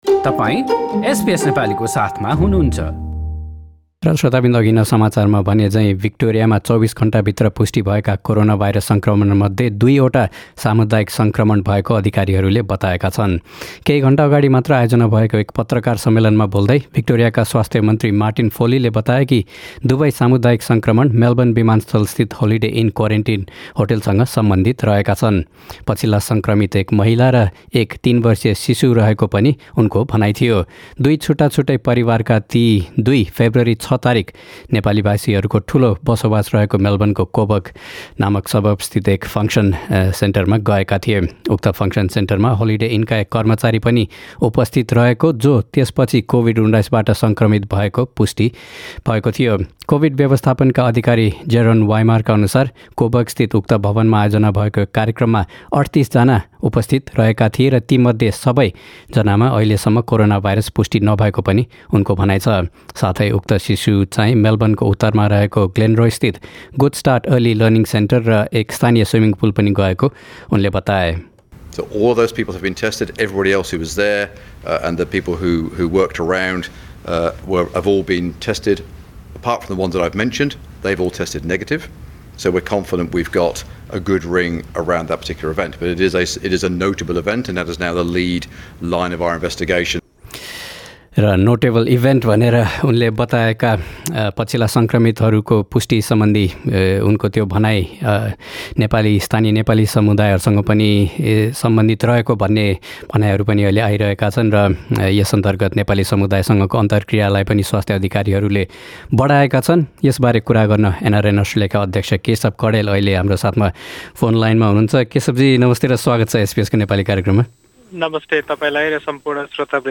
A text version of this news report is available in the Nepali language section of our website.